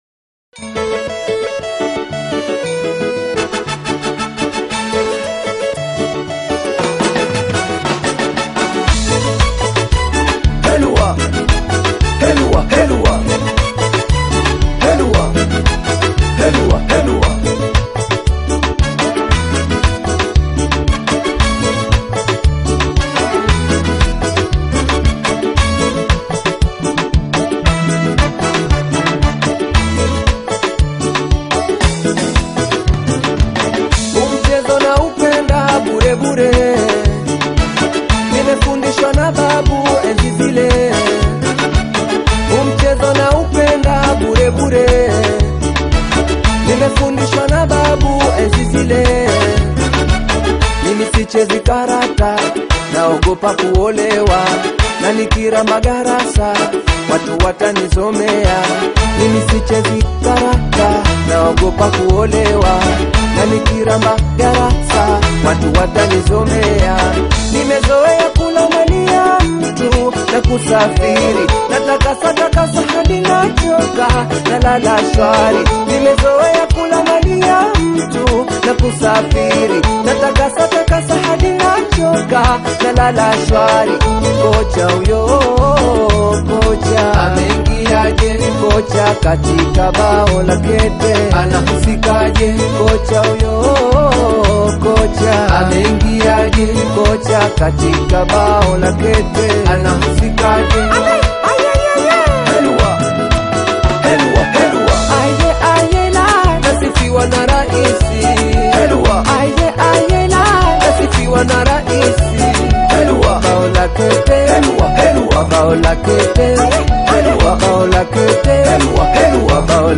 BAIKOKO TANGA Mduara